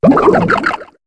Cri de Méios dans Pokémon HOME.